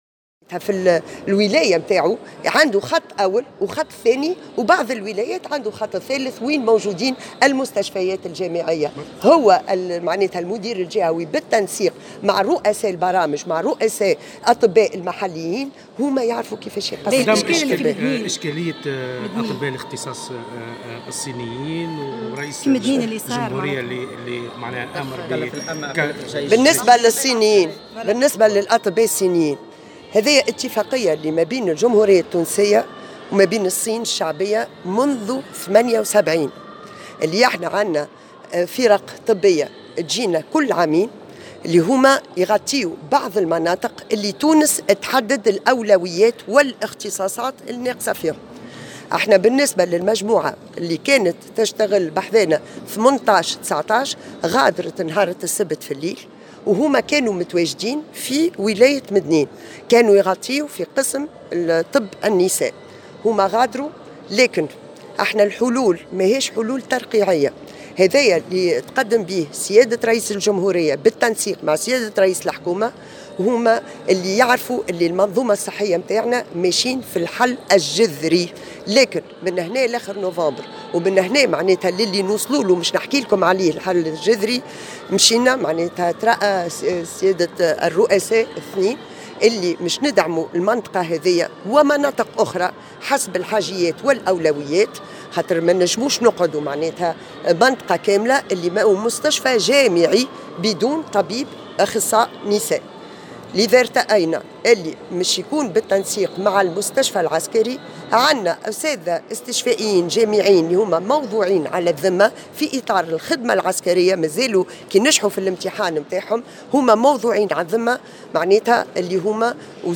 كشفت وزيرة الصحة بالنيابة سنية بالشّيخ، في تصريح لمراسل الجوهرة اف أم اليوم...